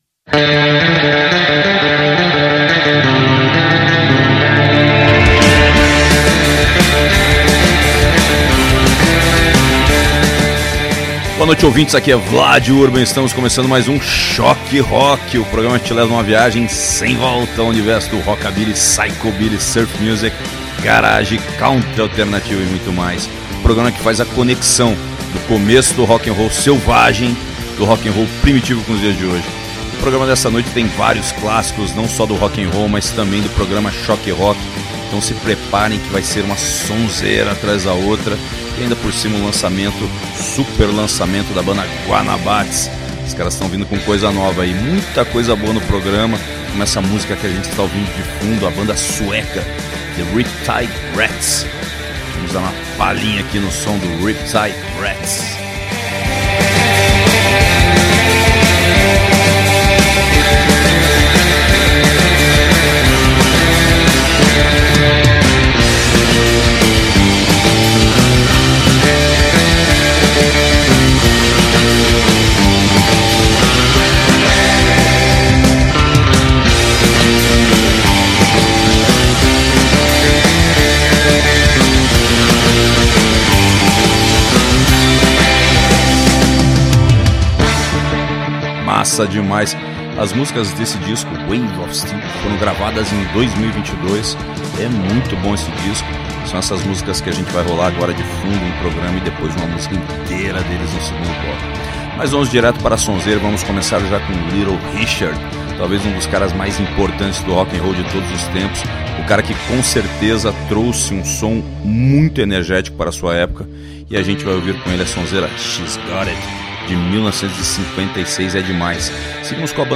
Shock Rock #6: confira o melhor do rockabilly e R&B underground | AlmA Londrina Rádio Web
No Shock Rock de hoje, episódio 6, o resgate do melhor do rock’n’roll dos anos 50, além de nomes obscuros do rockabilly, R&B, bluegrass